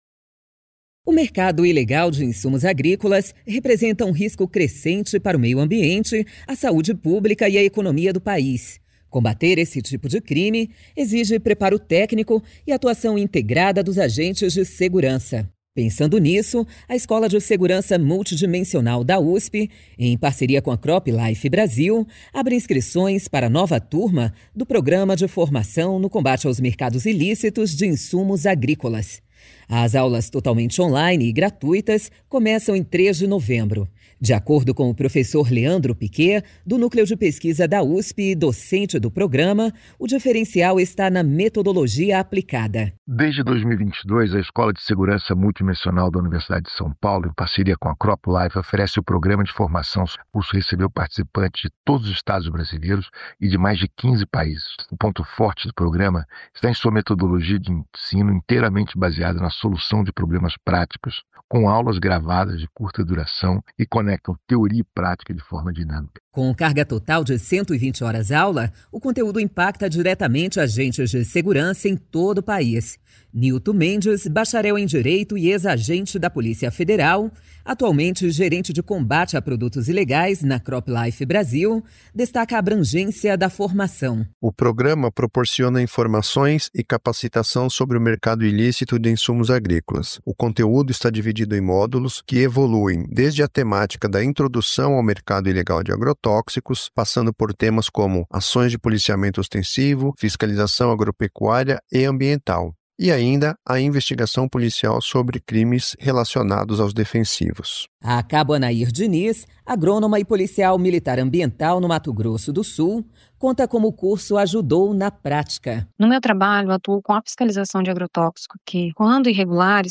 [Rádio] CropLife Brasil e USP promovem 4ª edição do curso - CropLife